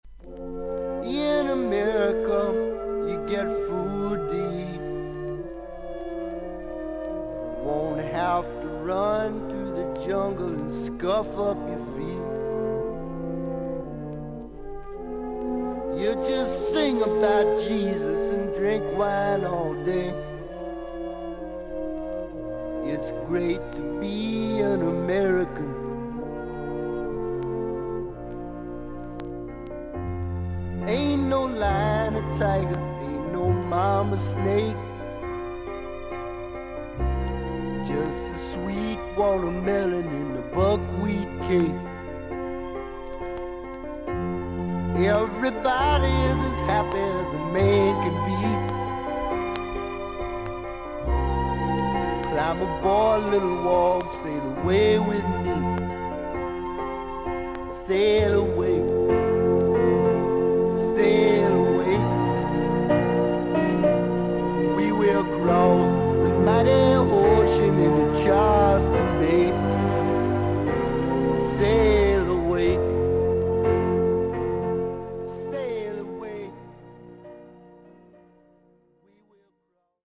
アメリカ盤 / 12インチ LP レコード / ステレオ盤
少々軽いパチノイズの箇所あり。少々サーフィス・ノイズあり。クリアな音です。